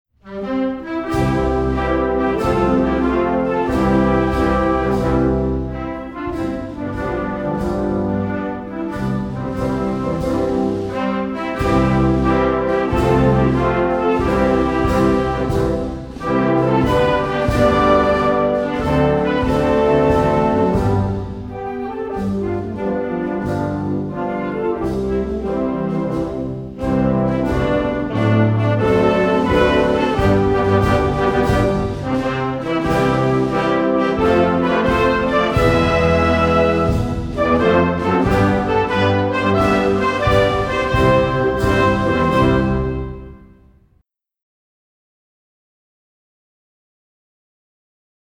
เพลงชาติ (วงโยวาทิต)